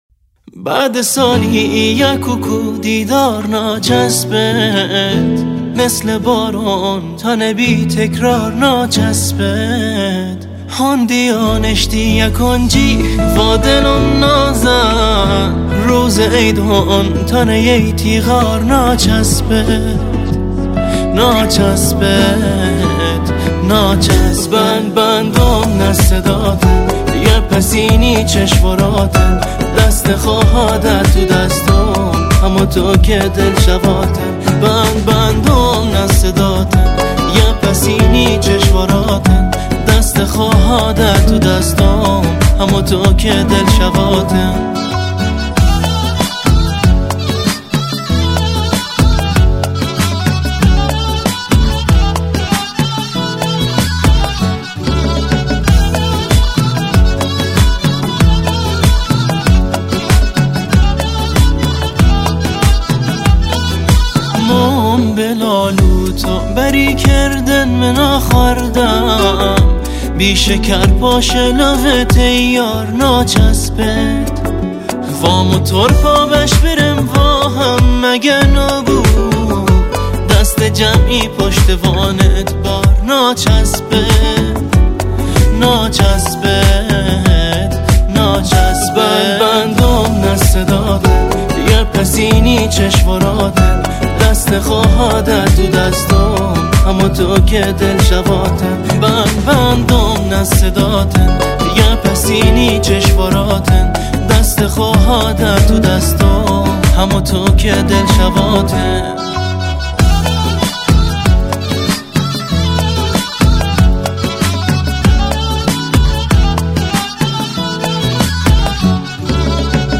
آهنگ بندری